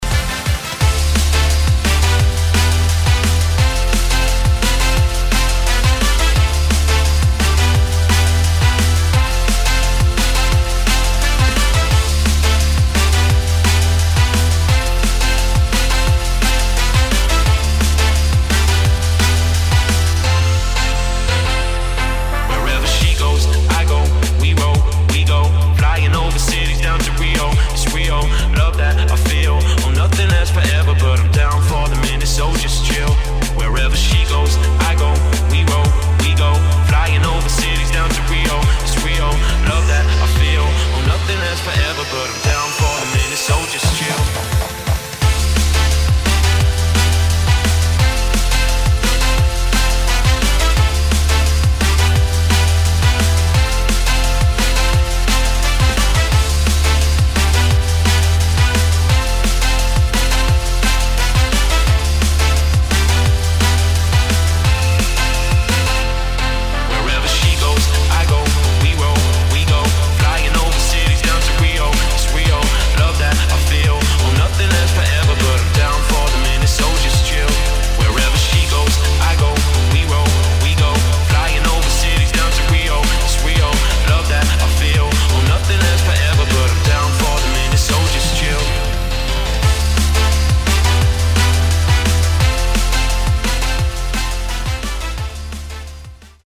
[ DRUM'N'BASS / POP / DUBSTEP ]